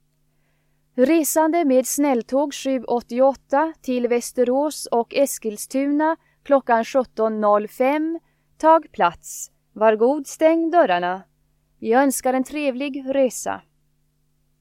Högtalarutrop Stockholms central